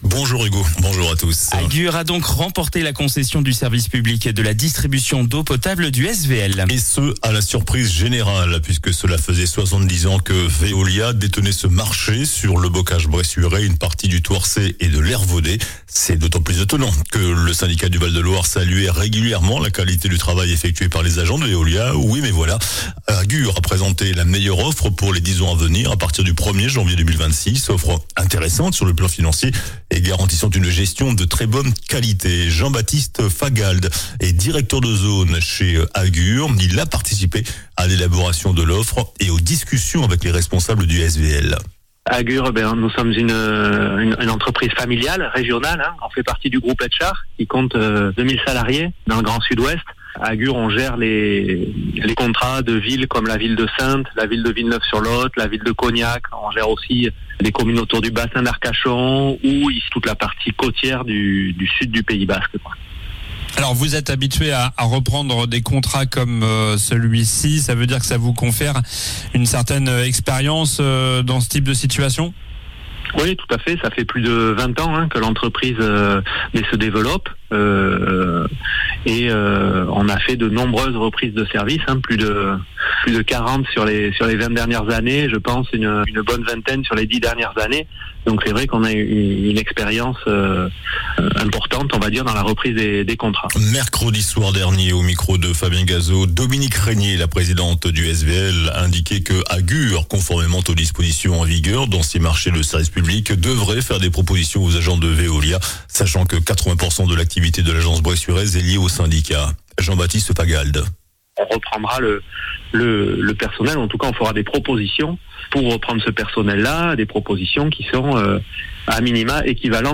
JOURNAL DU SAMEDI 07 JUIN